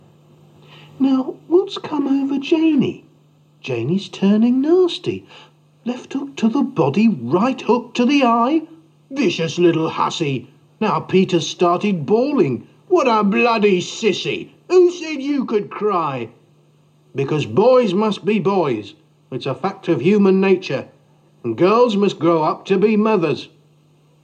Boyswillbeboys_stanza3.mp3